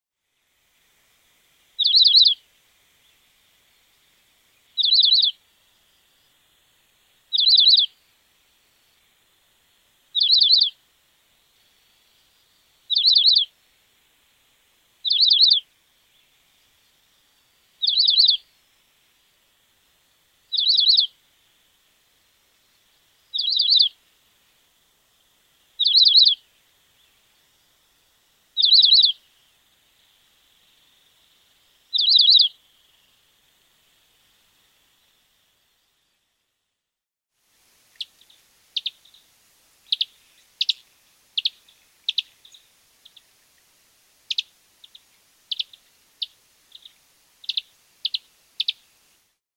Saltatricula multicolor.mp3